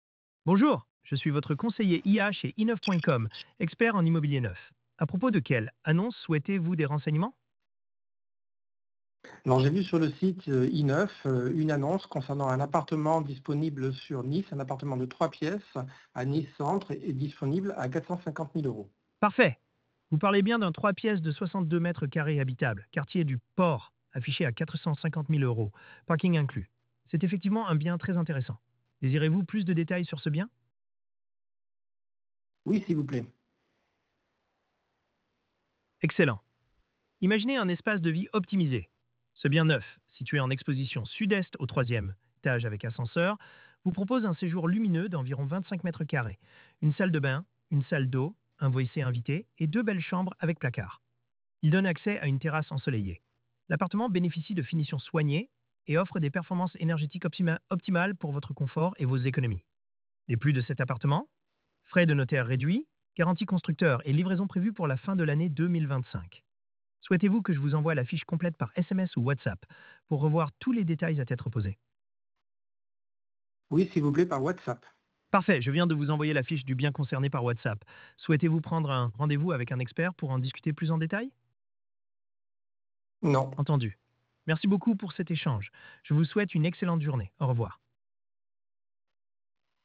Écoutez notre IA en action
Découvrez notre agent IA spécialiste de l'immobilier dans des cas concrets